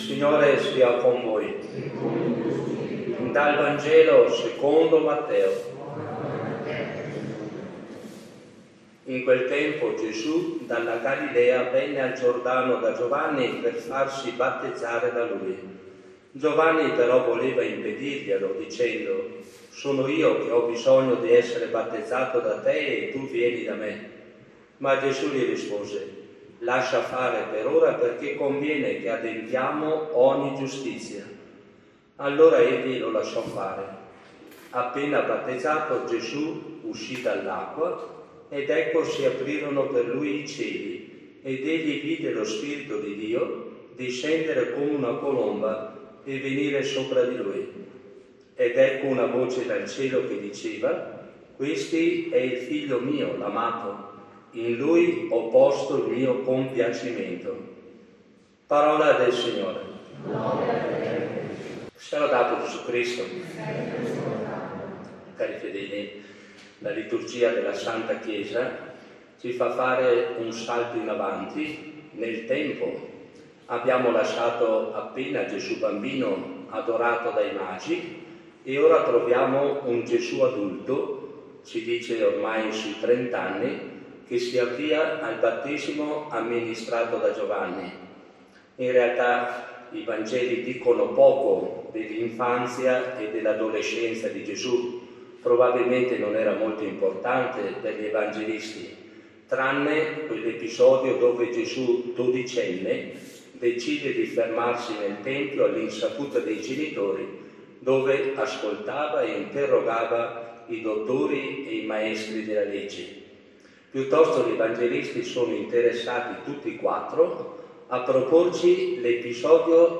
Omelia
SFM-Omelia-8-1-23.mp3